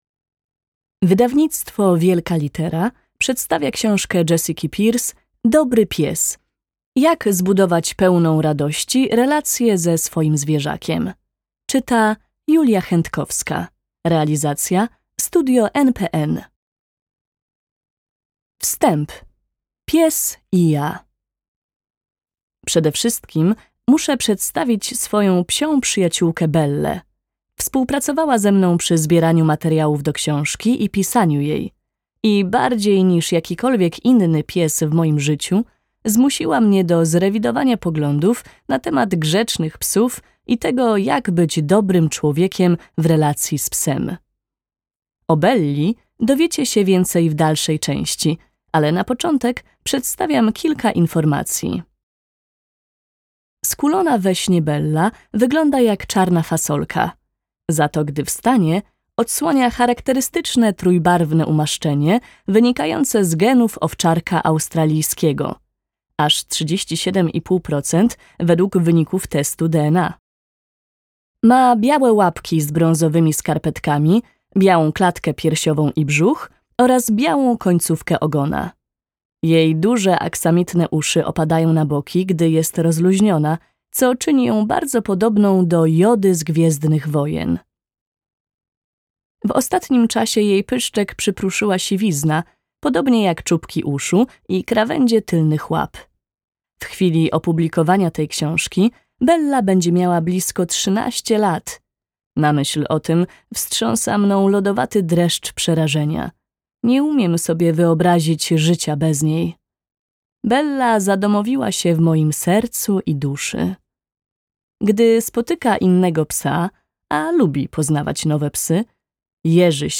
Dooobry pies - Jessica Pierce - audiobook